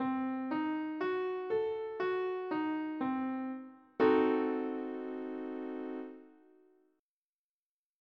Example: C-Eb-Gb-Bbb(A)
C diminished 7th chord
Diminished 7th chords are masterful at expressing doom, tension, and fear through music. Though they can be found in popular music, they’re mostly featured in art music because they are so tense and dissonant.
Cdim7.mp3